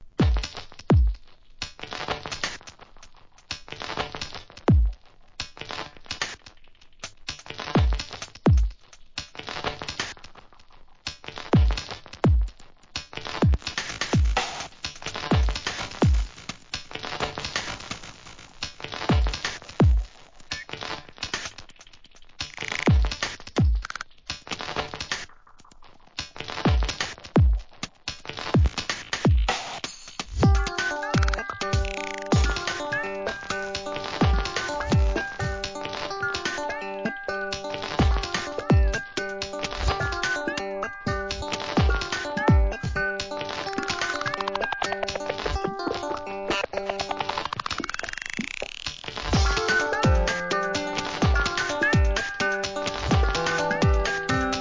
ドイツ発エレクトロニカ、ブレイクビーツ!!